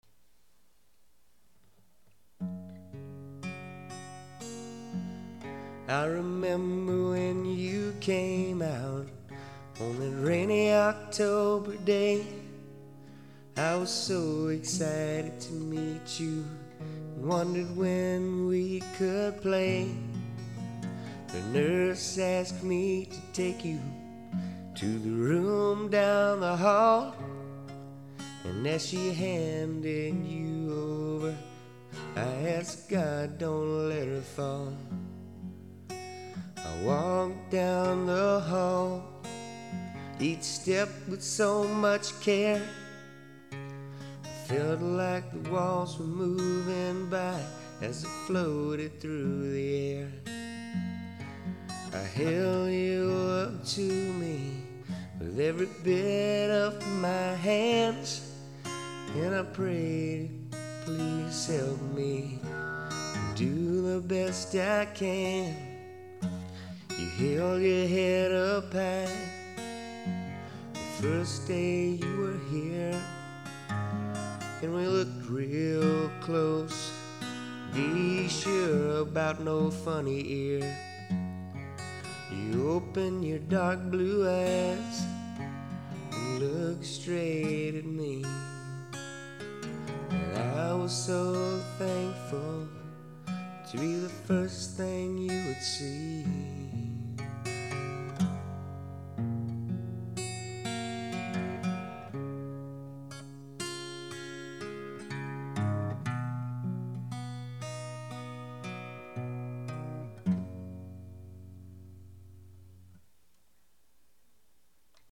Country-rock